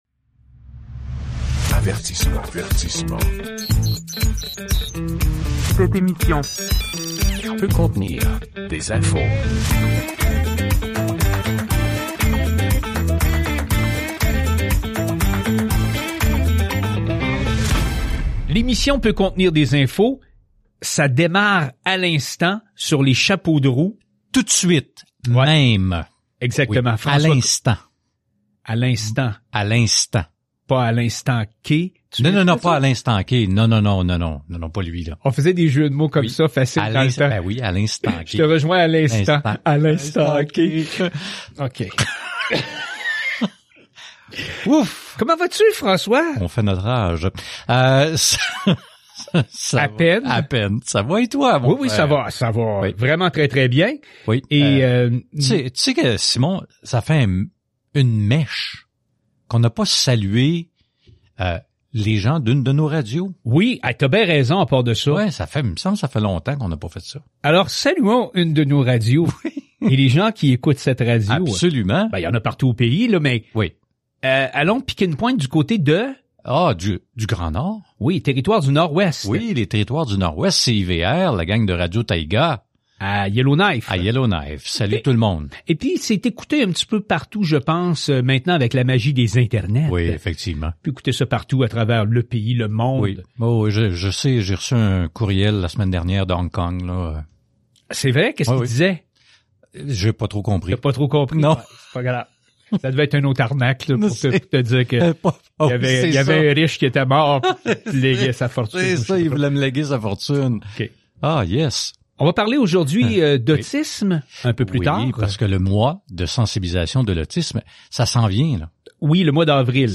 L'entrevue complète à compter de 12:54.